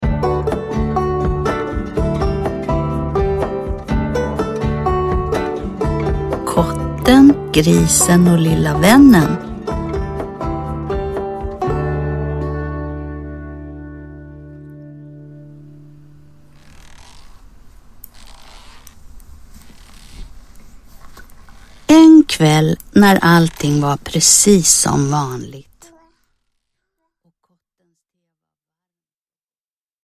Kotten, Grisen och lilla vännen – Ljudbok – Laddas ner